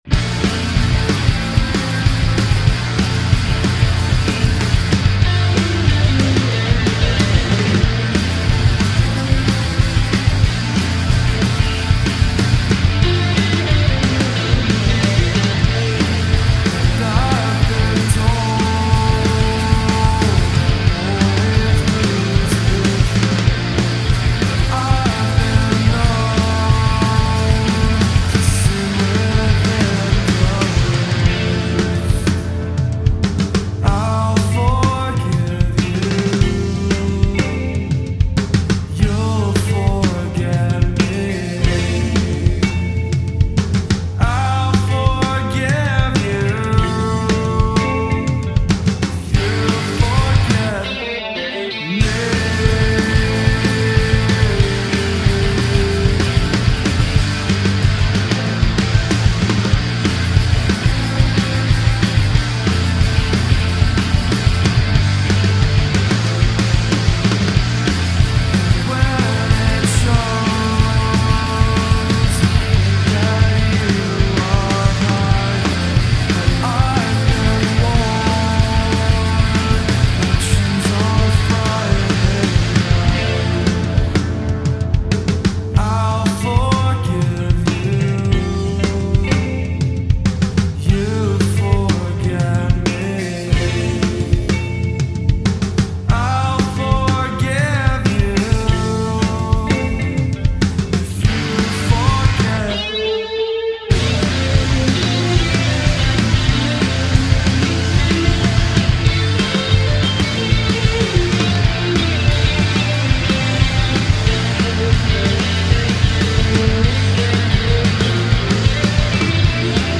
punk/shoegaze band
reportedly recorded in a Basement in Southbridge.